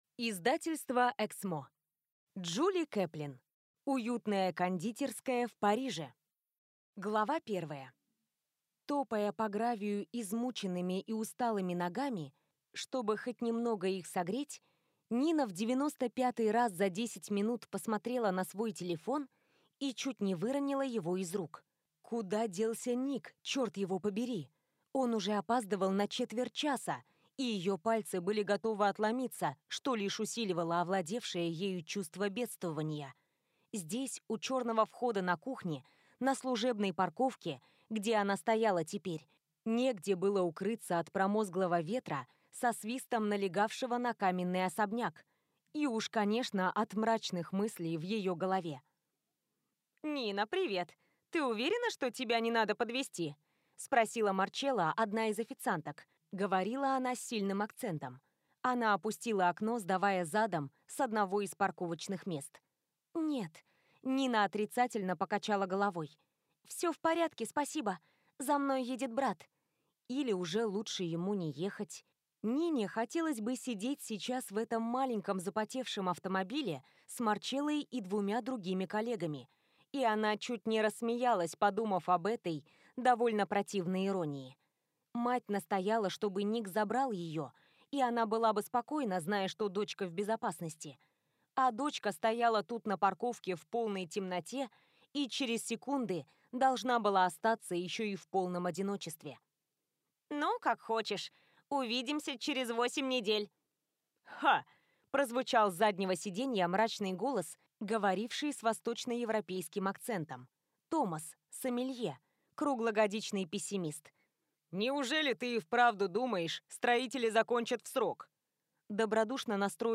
Аудиокнига Уютная кондитерская в Париже | Библиотека аудиокниг